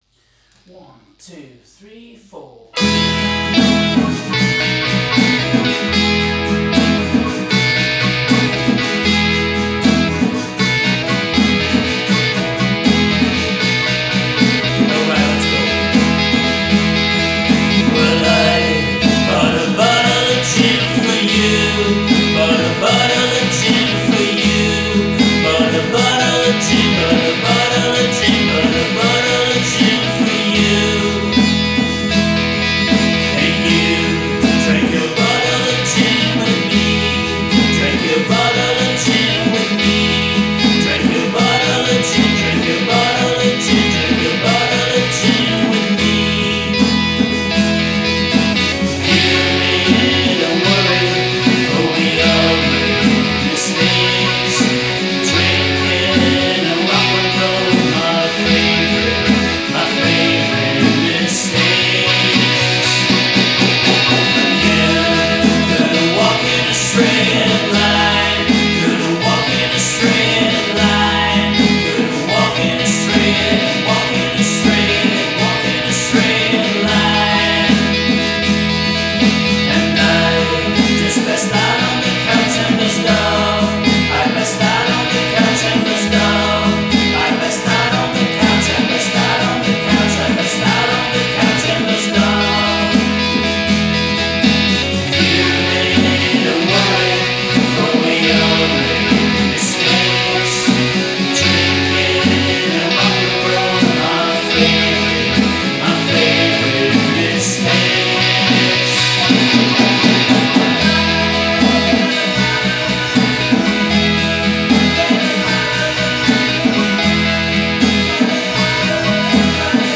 vocals, guitars, bass, drums, keyboards